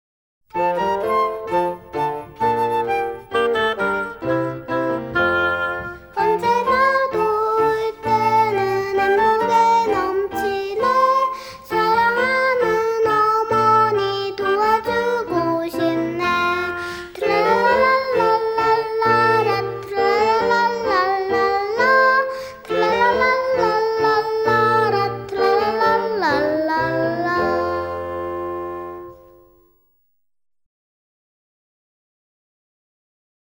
Composer: German folk song